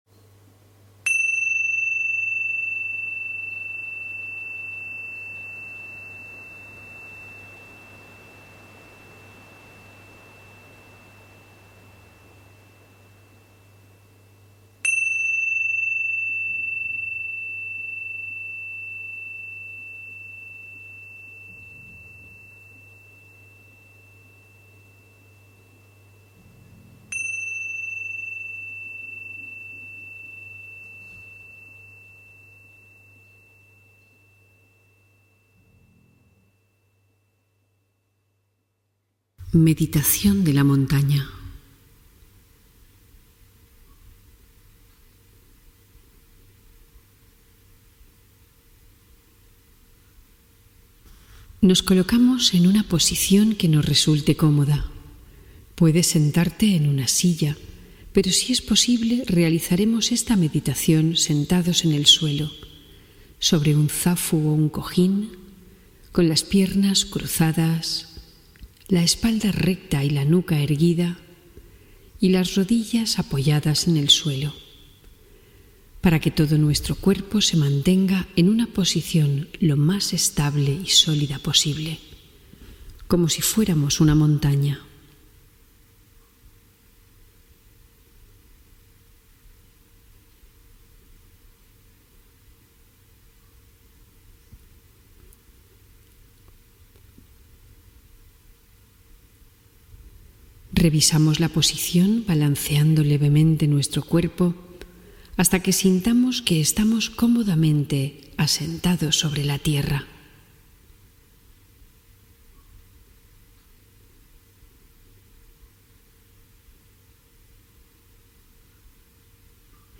La Montaña Interior Breve: Meditación de Calma y Presencia